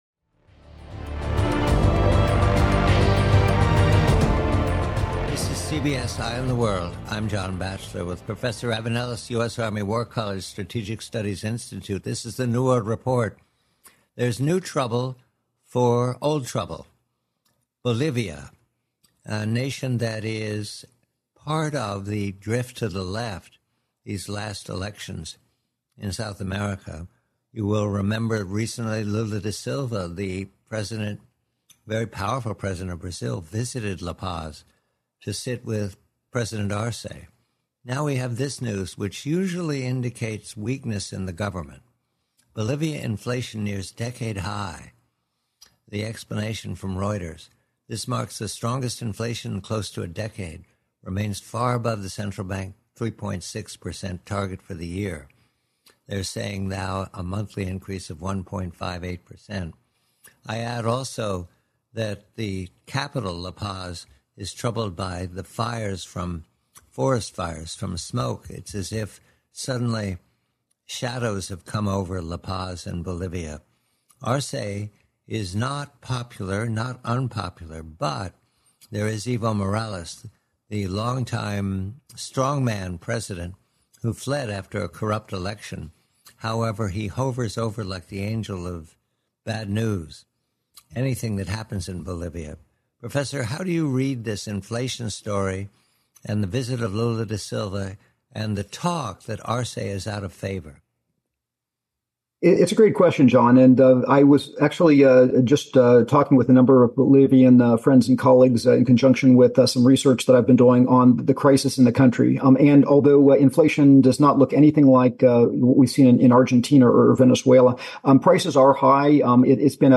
Bolivia sinking. Report